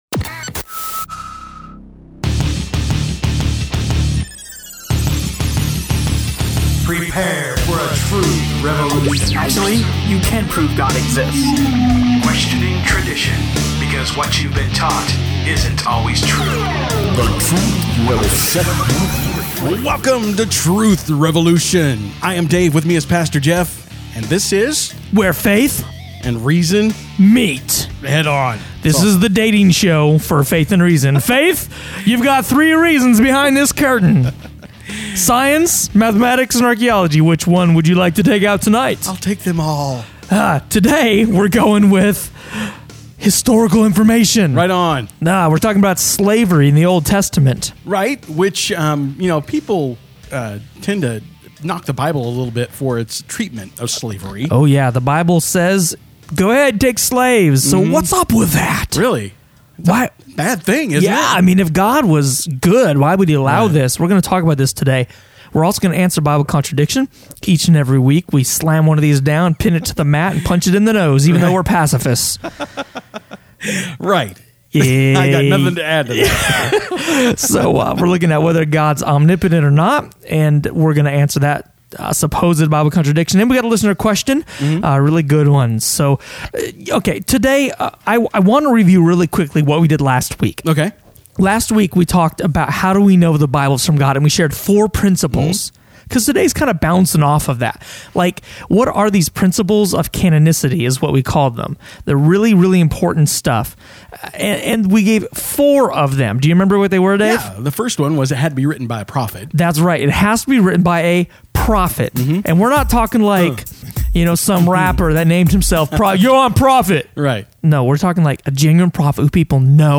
Slavery in the Bible – Truth Revolution Radio Show